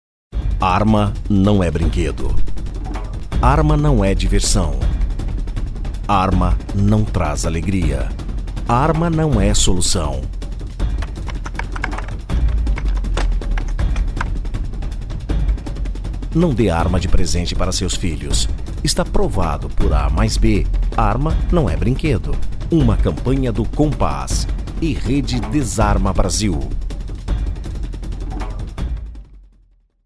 2. Spot para Rádios (
Spot_arma_nao_e_brinquedo_30seg.mp3